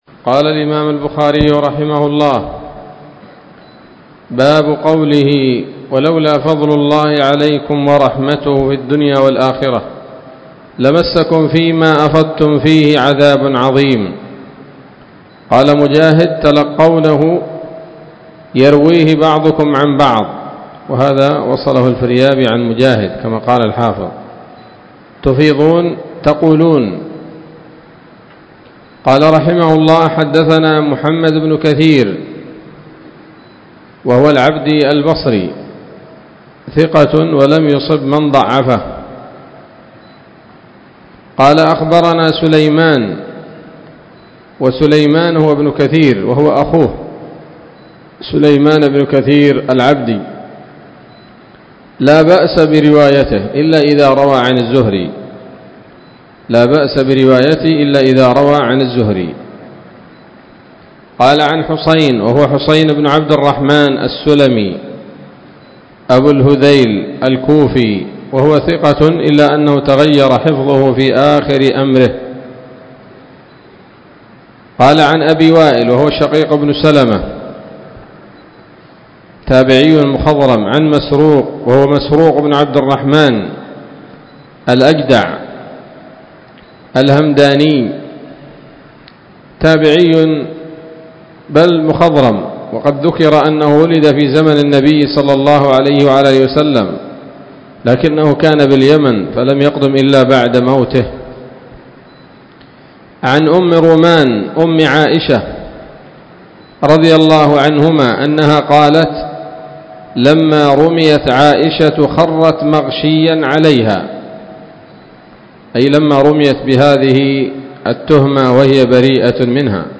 الدرس الرابع والثمانون بعد المائة من كتاب التفسير من صحيح الإمام البخاري